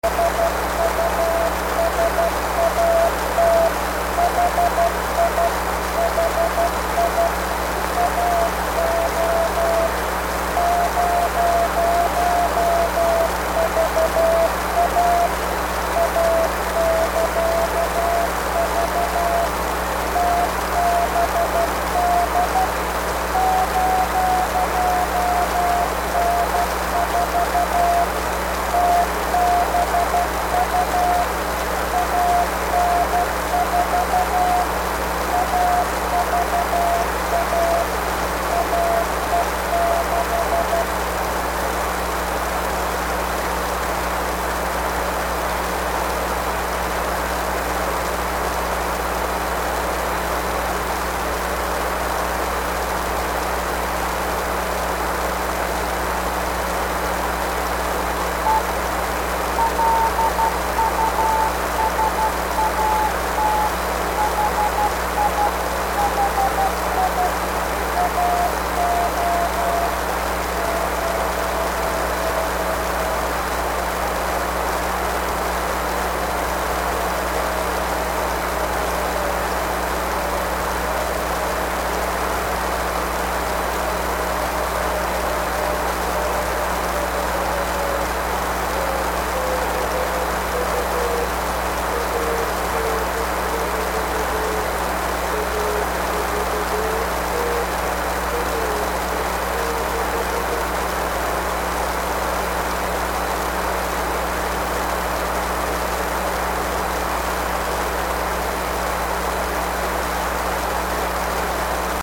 I recorded this right off the air using my new
This is a recording of the LUSAT-OSCAR 19 (website) CW beacon which was on 437.120MHz: